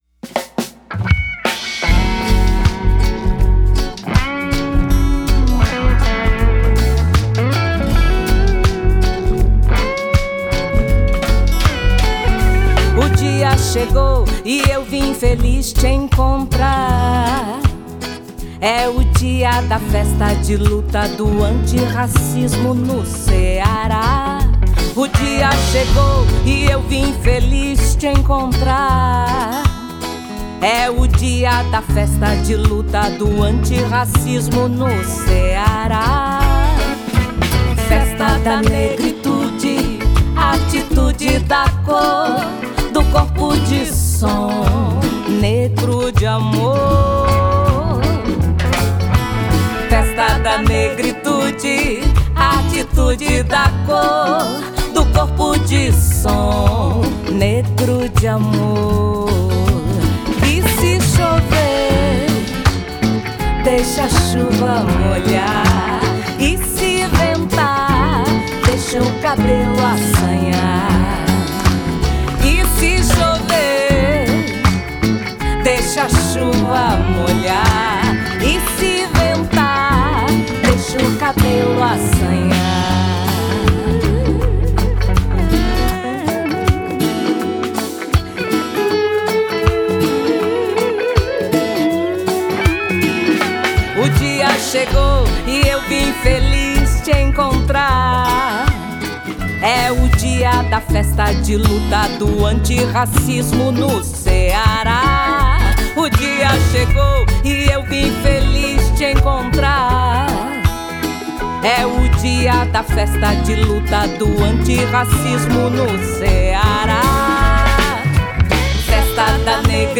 Violão nylon, violão aço e guitarras
Bateria, percussões e percussão eletrônica
Baixo, vocal, arranjo e produção musical
Gravado, mixado e masterizado
reggae